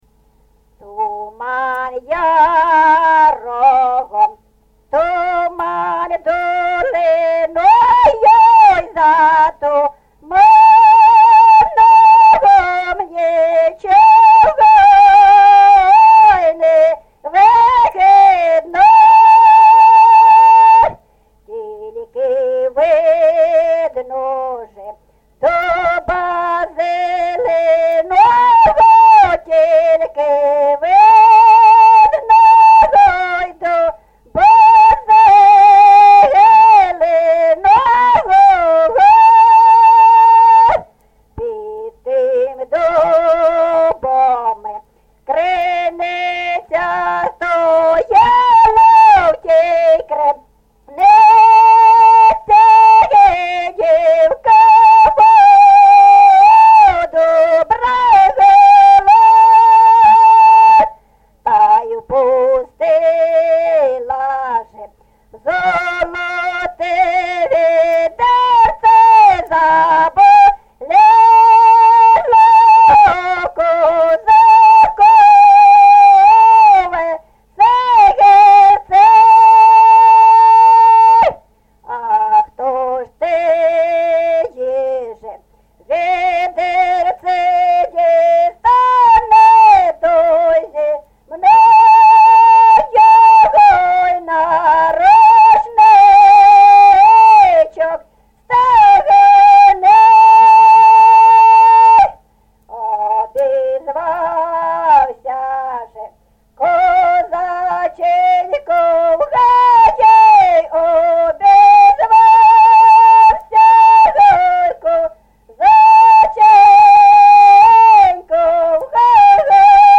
ЖанрПісні з особистого та родинного життя
Місце записус. Свято-Покровське, Бахмутський район, Донецька обл., Україна, Слобожанщина